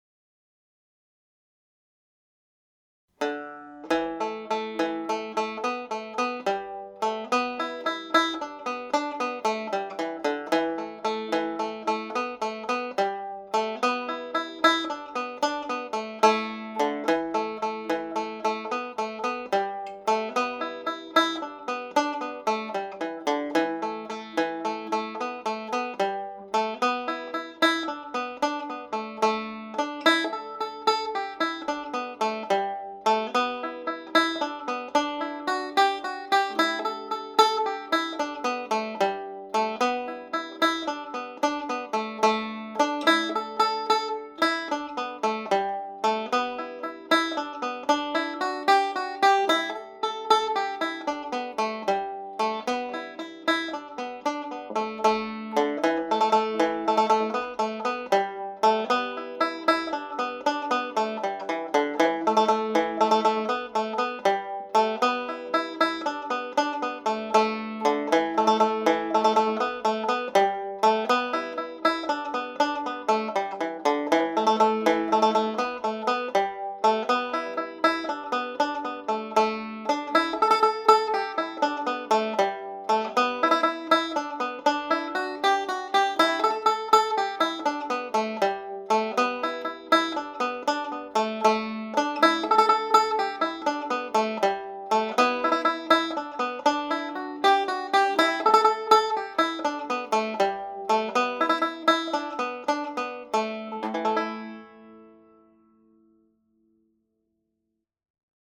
• banjo scale
Lilting Banshee played a little faster with triplets added